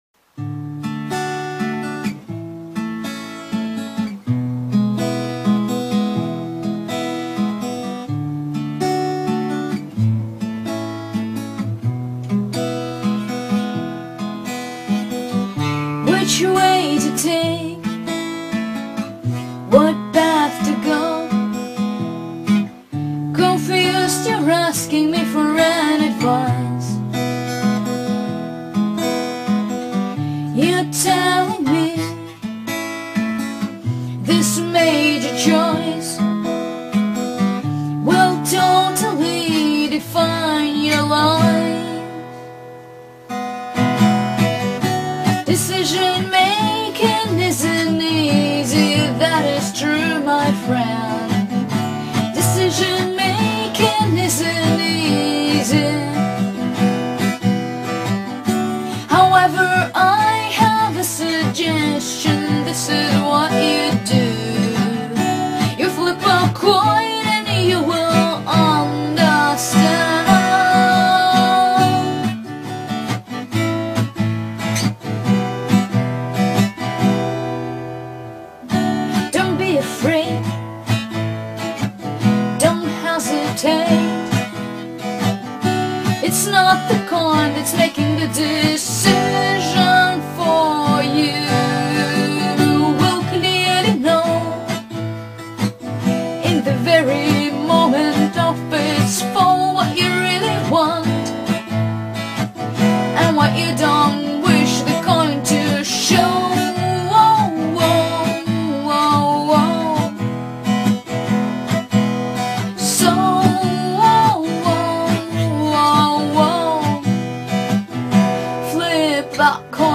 Форум / Forum » VIDEOS | ВИДЕОКЛИПЫ » Original song » Flip a Coin - video clip ((original song))